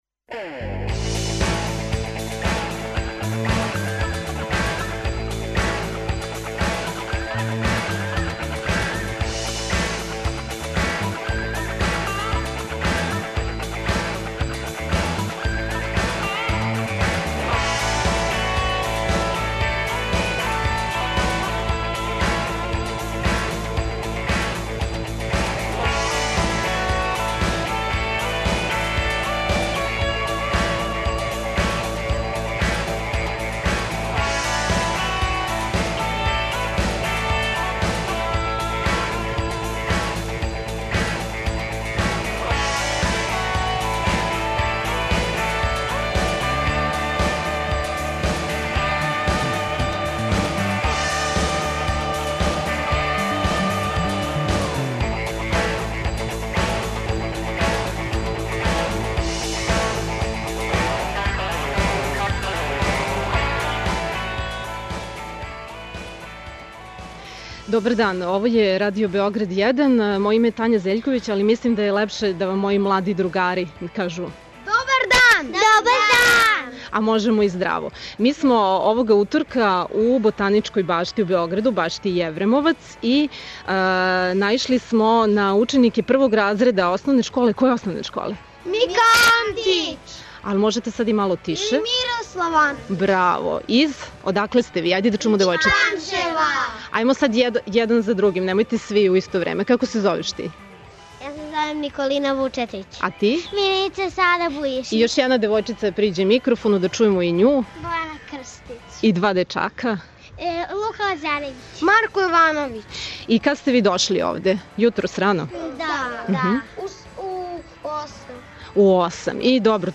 На Европски дан паркова, преподневни магазин емитоваћемо из београдске Ботаничке баште - Јевремовац. Током емисије, сазнаћемо више о природним добрима, националним парковима и осталим зеленим површинама читаве Србије.